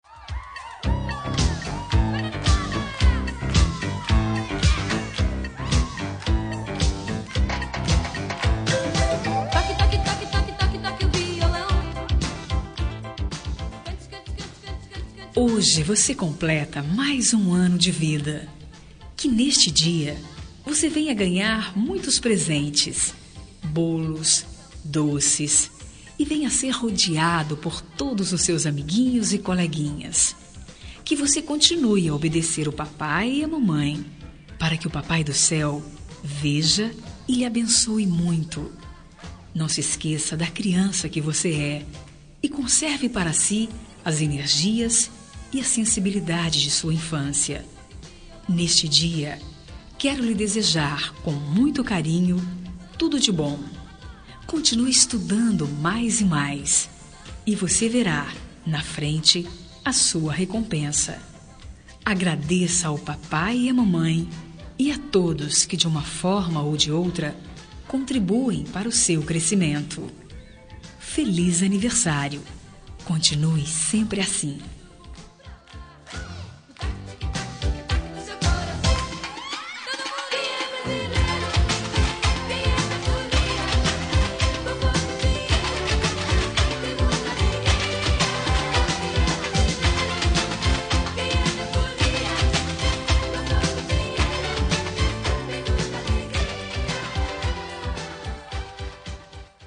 Telemensagem de Criança – Voz Feminina – Cód: 8125 – Legal